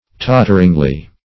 totteringly - definition of totteringly - synonyms, pronunciation, spelling from Free Dictionary Search Result for " totteringly" : The Collaborative International Dictionary of English v.0.48: Totteringly \Tot"ter*ing*ly\, adv. In a tottering manner.